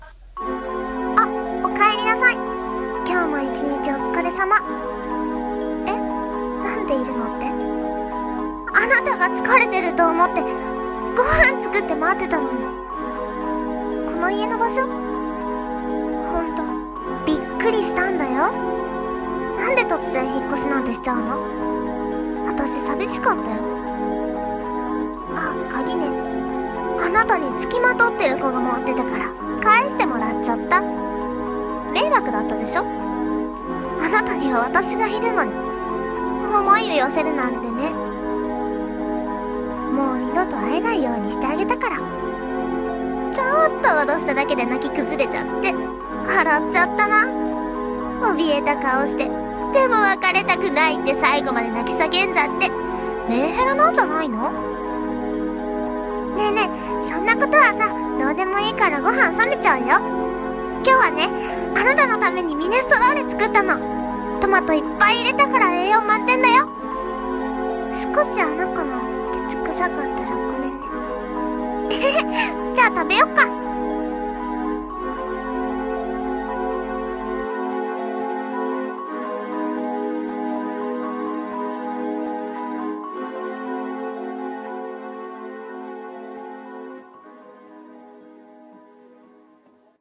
朗読